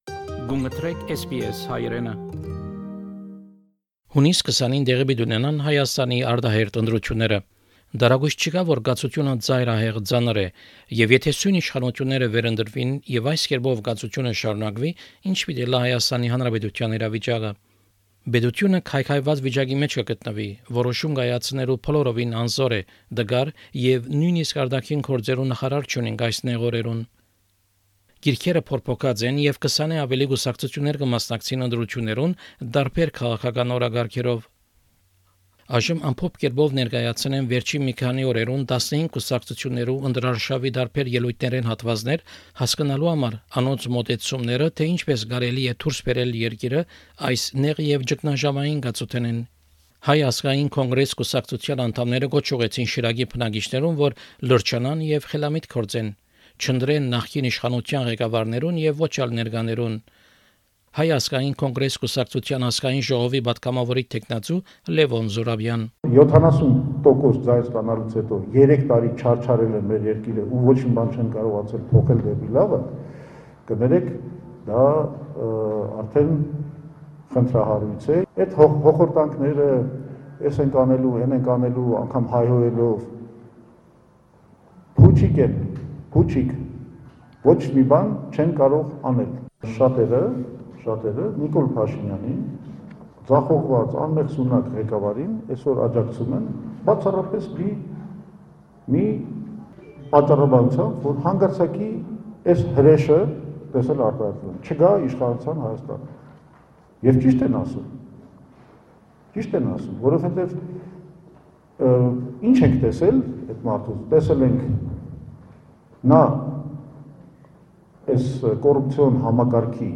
On June 20, Armenia will hold a snap parliamentary election. This program looks at several (15) political parties with excerpts from their recent election campaigns.